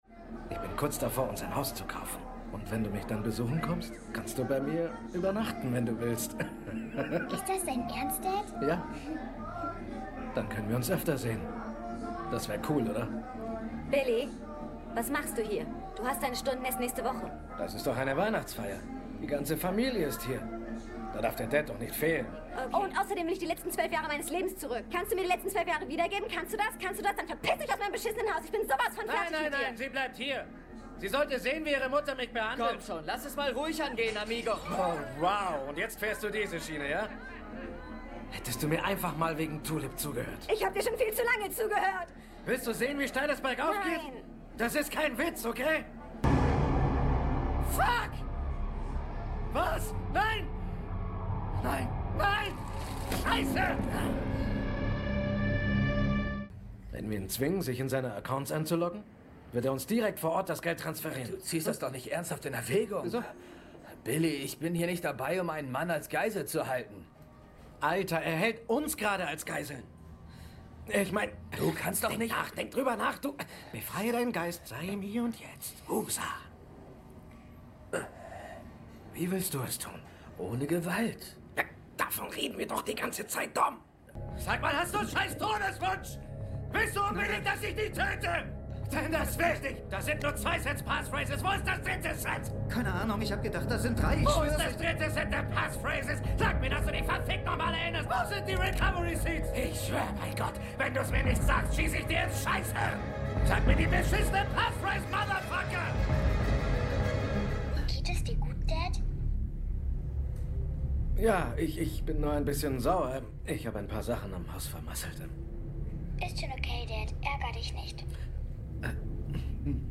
markant, sehr variabel
Mittel plus (35-65)
Lip-Sync (Synchron)